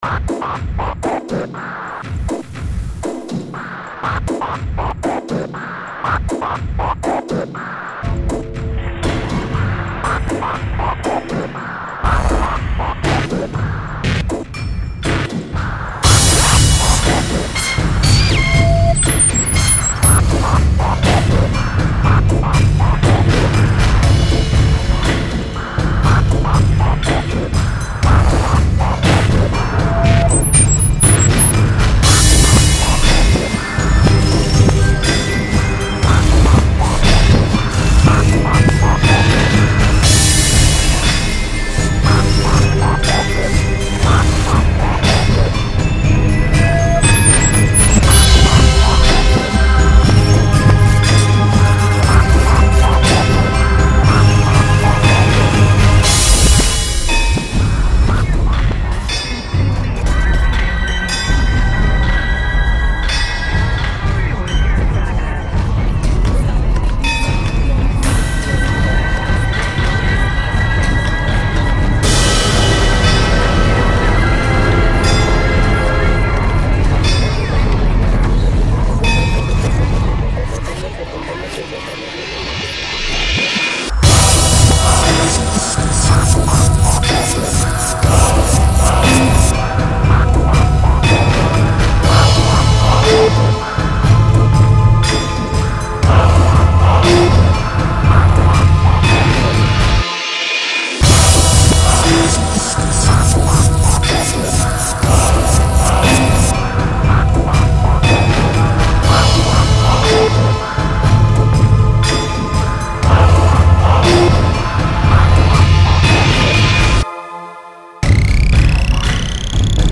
A mon goût, des bruitage peuvent très bien être utilises, mais plutôt de manière rythmique comme des percussions, et non comme une longue bande son concrète trop suggestive.